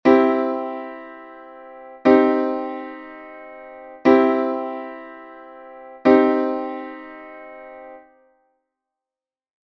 Listen to the example below as it alternates back and forth between a C Major chord and a C Minor chord.
major-minor-triads.mp3